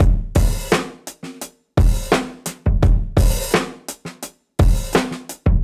Index of /musicradar/dusty-funk-samples/Beats/85bpm
DF_BeatB_85-02.wav